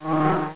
WASP_SML.mp3